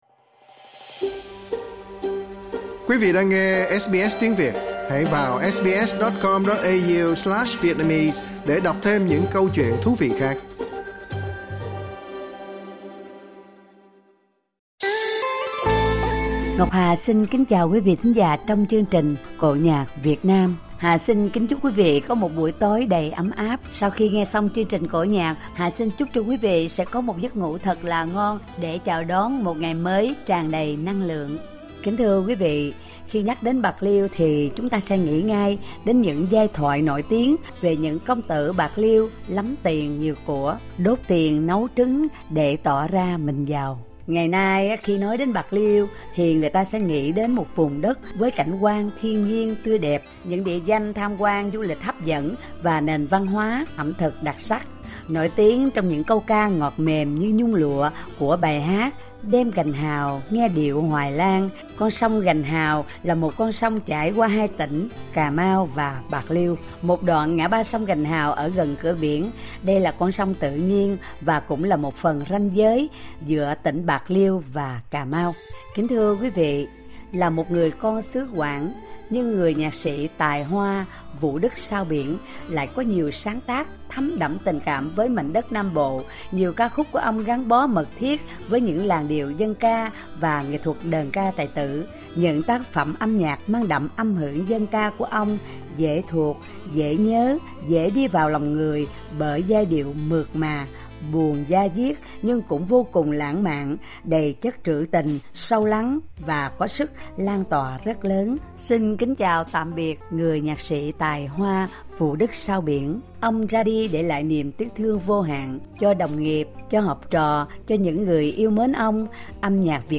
tại Melbourne